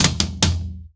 TOM     4C.wav